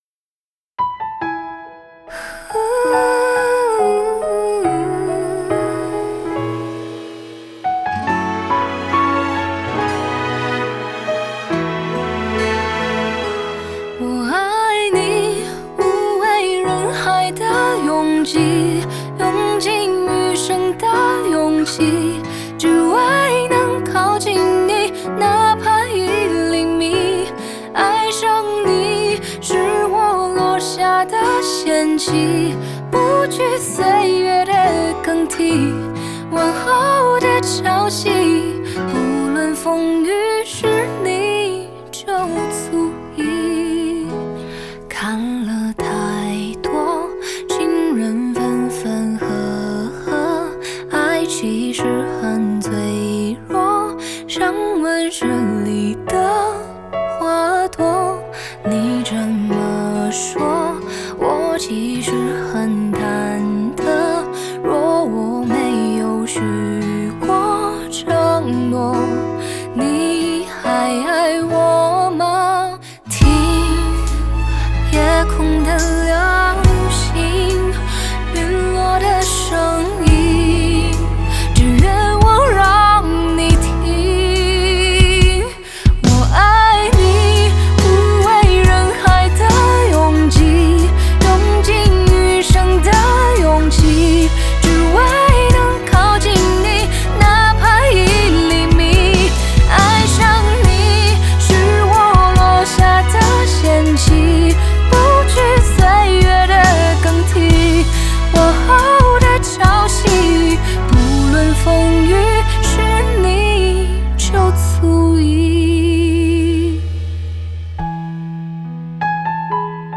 Ps：在线试听为压缩音质节选，体验无损音质请下载完整版
吉他
录音室：好听音乐
混音室：FLOWSIXTEEN STUDIO